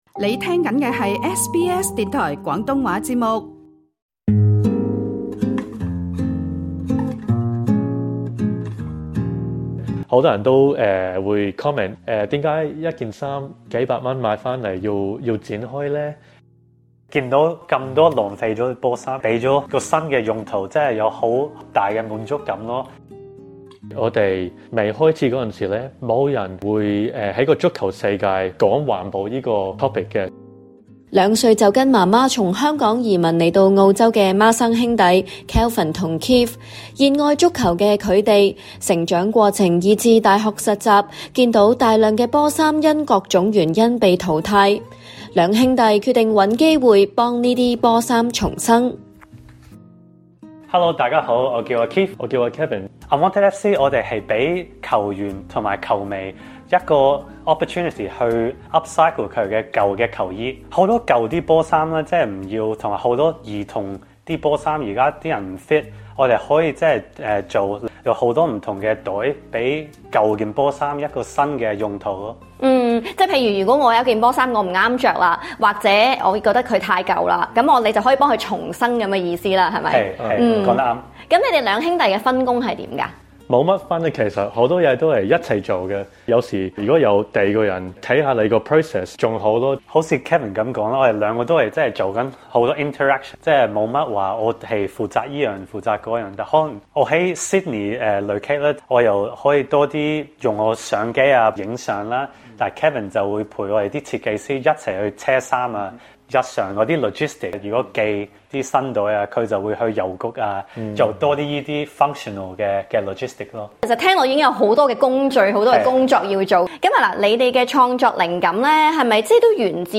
Credit: 被訪者提供 想知道更多關於第一件舊球衣升級再造的製成品，以至最難忘的球衣製作，請收聽今集【人物誌】足本版訪問。